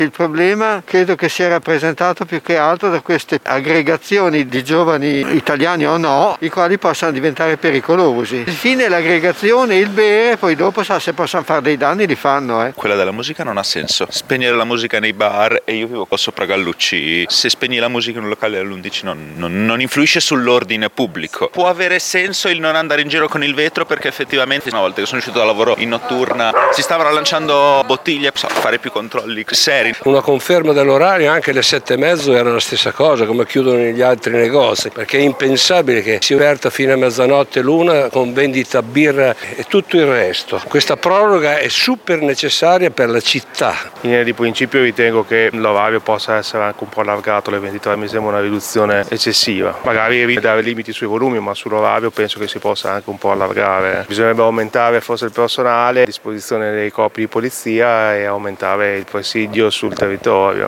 Sentiamo i pareri di chi abita in centro:
VOX-ORDINANZE.mp3